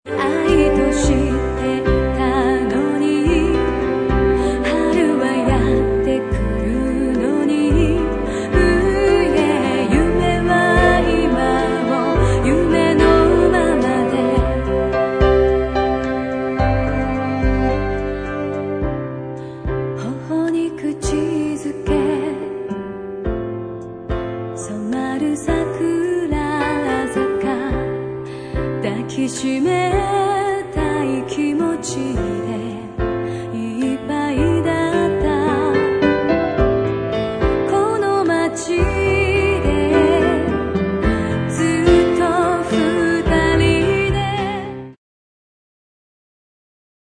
Vocal
Piano＆Chorus
Cello